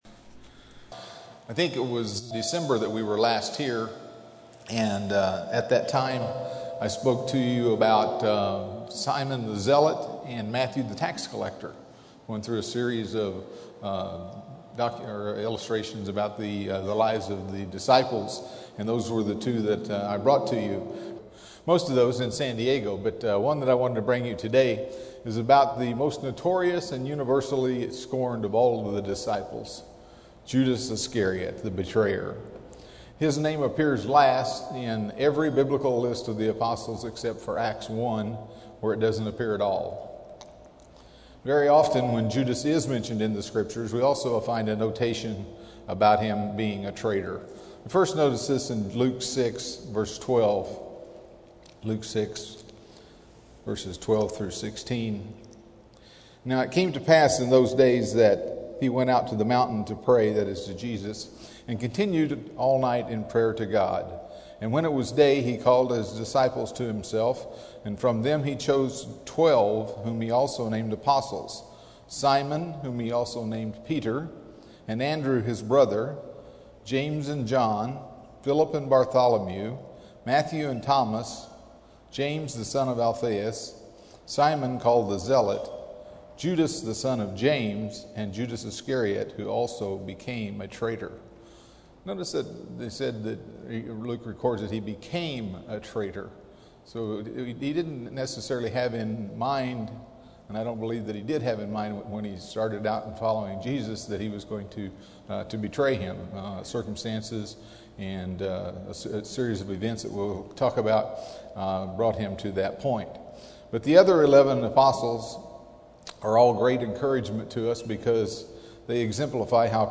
The life of Judas Iscariot, the betrayer of Jesus Christ, is examined from various aspects. This sermon discusses moral lessons we can learn from Judas Iscariot's life, his name, calling, disillusionment, greed, hypocrisy, and death.
Given in Los Angeles, CA